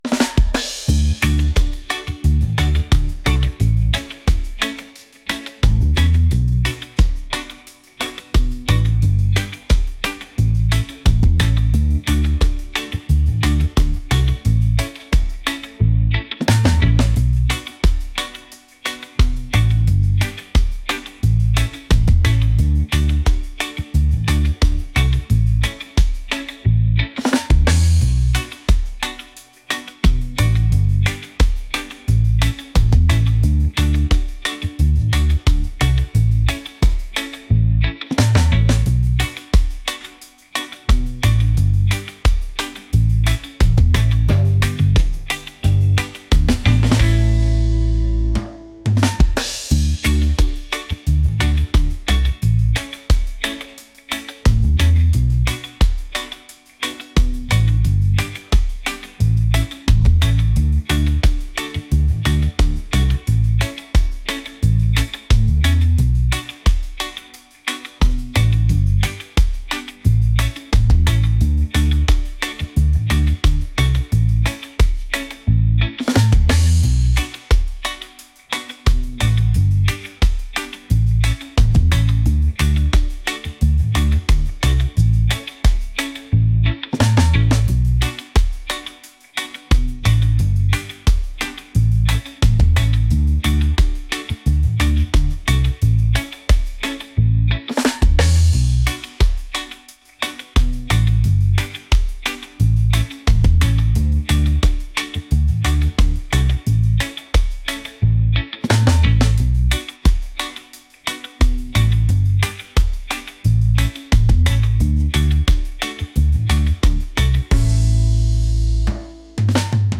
reggae | upbeat | groovy